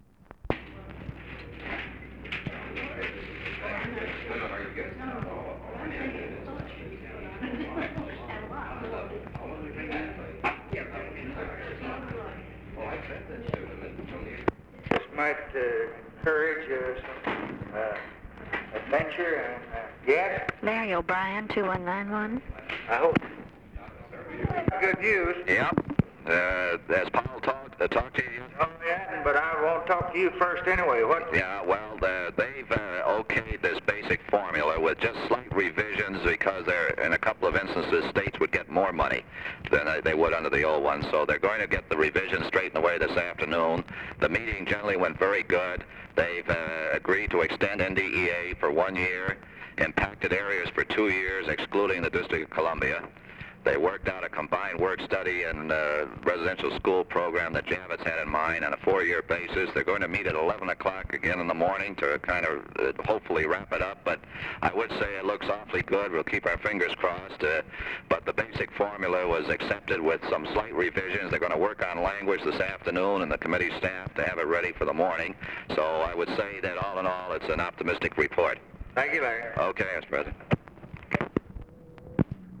Conversation with LARRY O'BRIEN, December 9, 1963
Secret White House Tapes